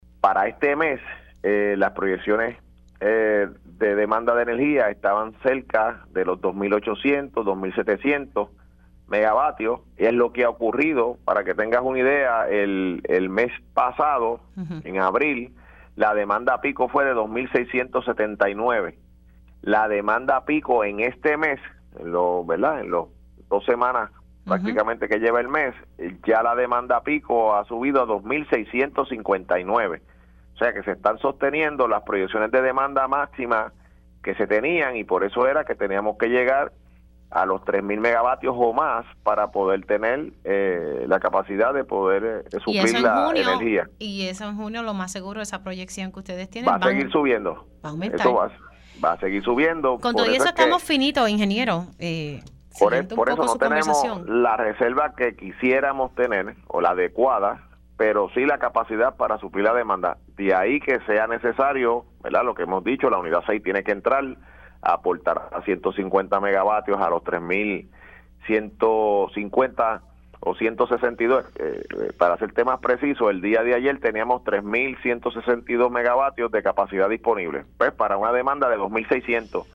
El Zar de Energía, Josué Colón reconoció en Pega’os en la Mañana que el país no cuenta con la reserva energética adecuada para cumplir con las proyecciones de demanda pico.